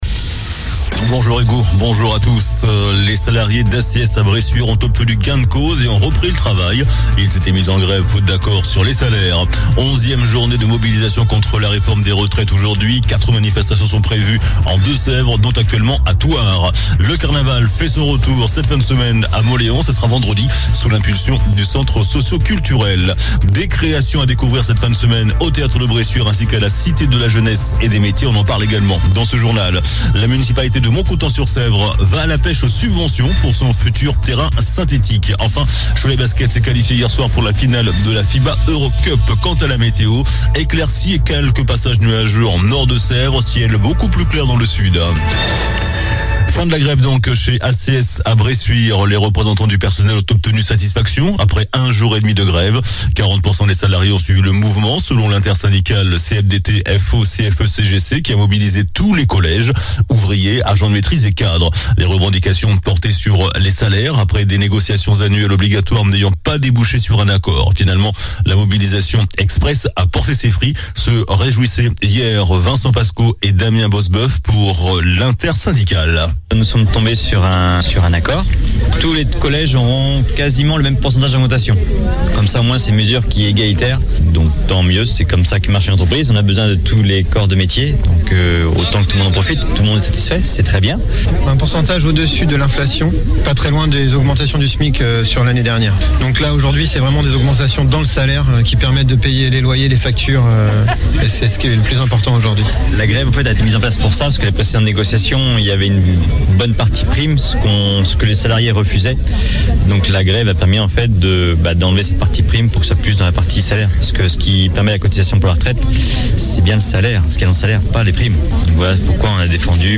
JOURNAL DU JEUDI 06 AVRIL ( MIDI )